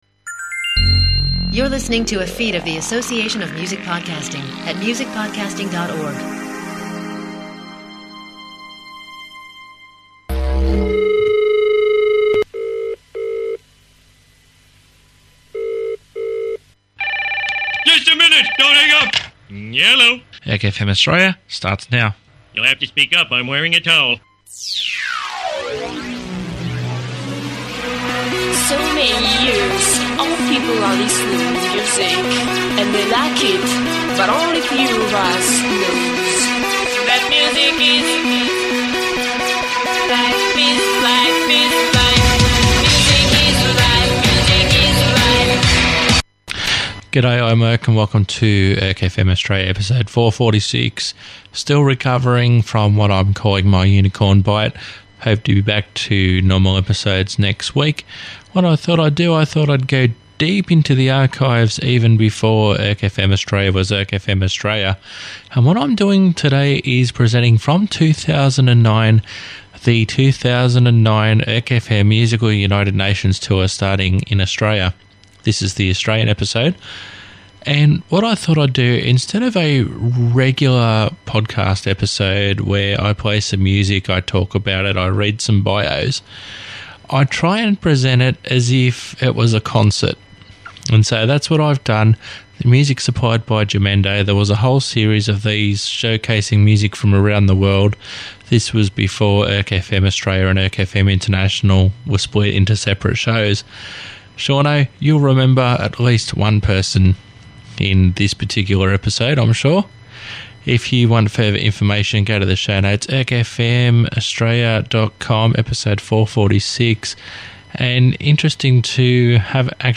Ambient noise obtained from The Free Sound Project.